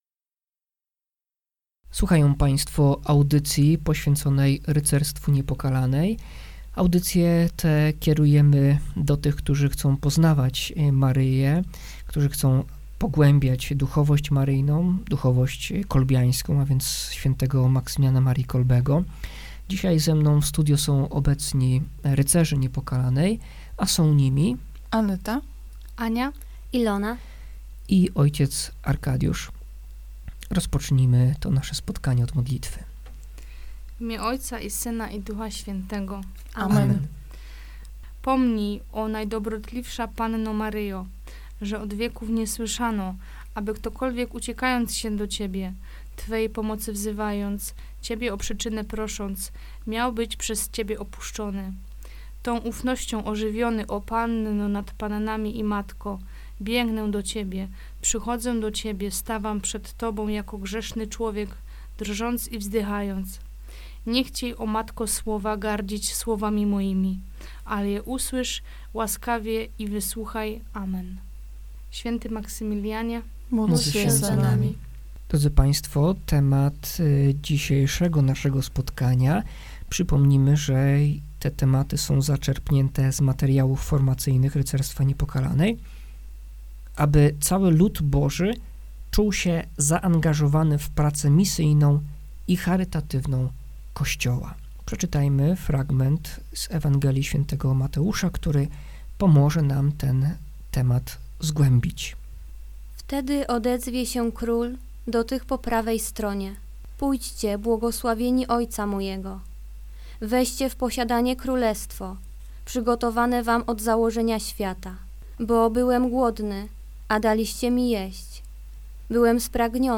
Audycję prowadzi franciszkanin